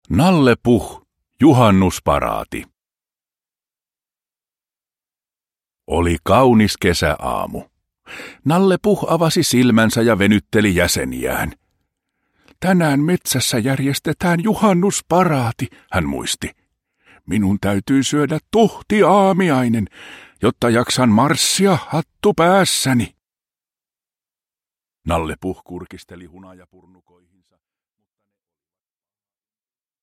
Nalle Puh. Juhannusparaati – Ljudbok – Laddas ner